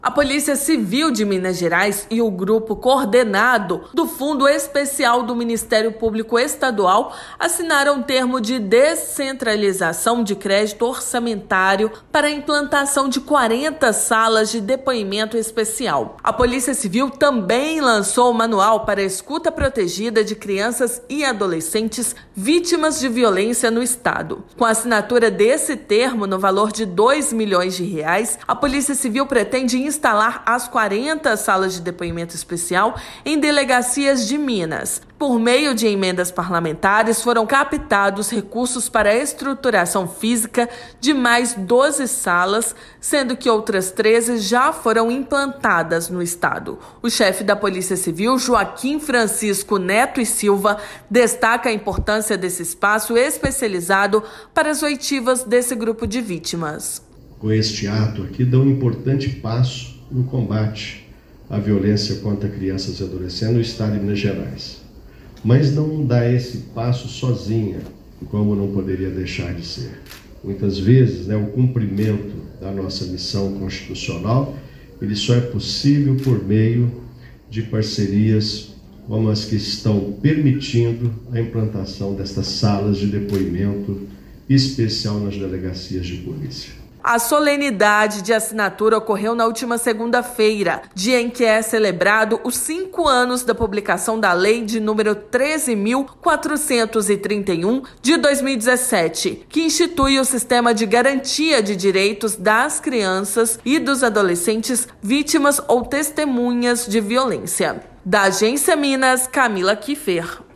Solenidade também marcou a apresentação do Manual para Escuta Protegida de Crianças e Adolescentes da Polícia Civil. Ouça matéria de rádio.